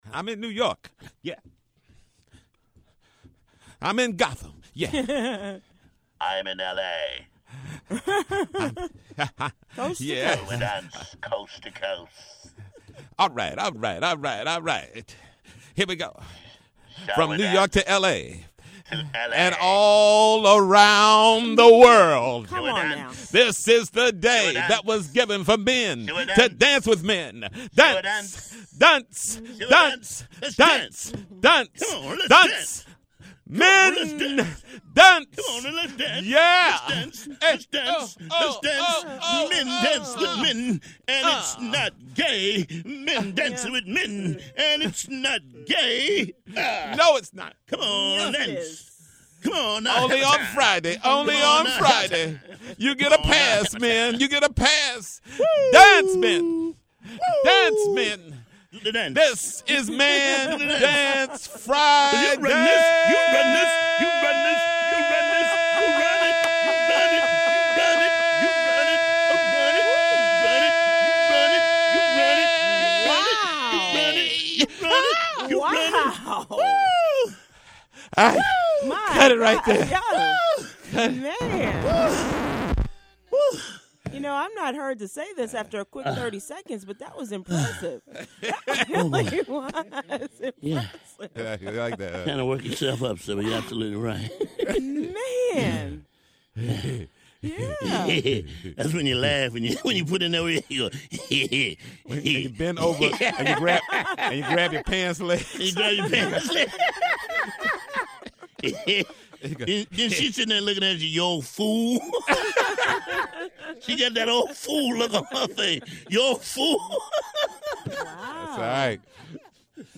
The TJMS is live in New York and they had loads of fun at the pre-Halloween Masquerade bash in the city. Click the link above to hear all about it and find out which costumes you don’t want to see everyday working professionals in.